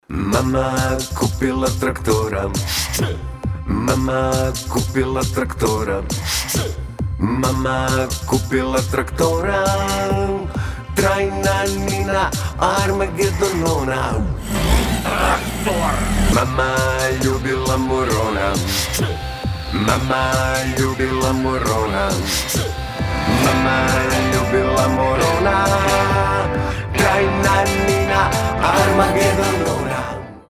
• Качество: 320, Stereo
необычные